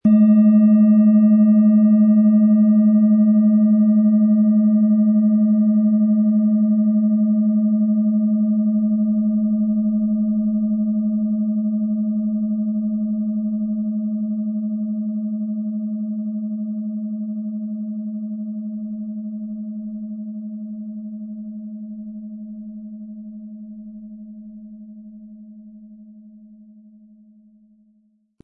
Tibetische Universal-Klangschale, Ø 18 cm, 1000-1100 Gramm, mit Klöppel
Wir haben ebendiese Klangschale beim Aufnehmen ausprobiert und den persönlich geprägten Eindruck, dass sie alle Körperregionen gleich deutlich zum Schwingen bringt.
MaterialBronze